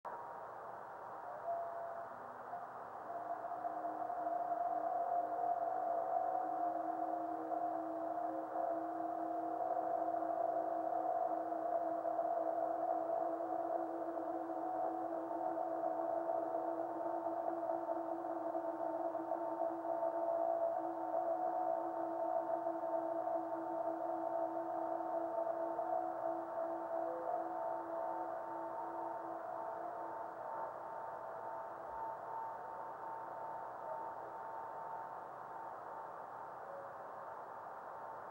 Below:  Radio spectrogram of the time of the meteor.  61.250 MHz reception above white line, 83.250 MHz below white line.